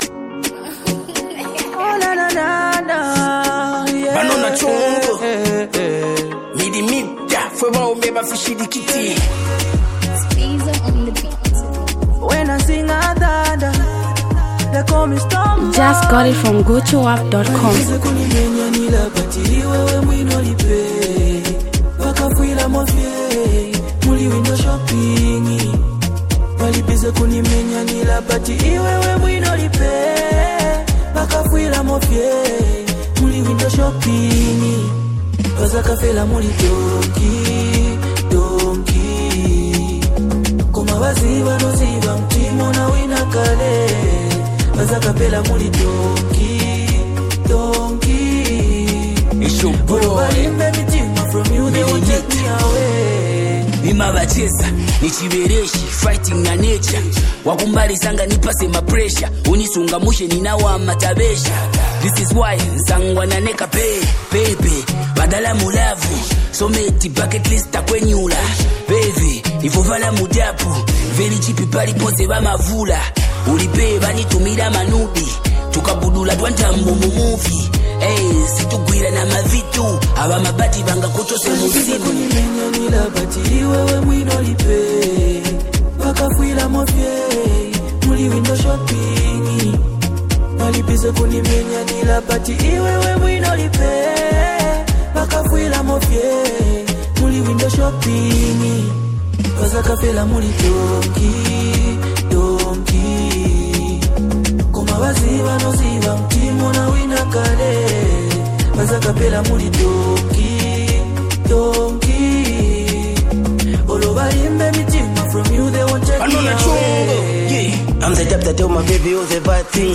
Afro-Hip-Hop Masterpiece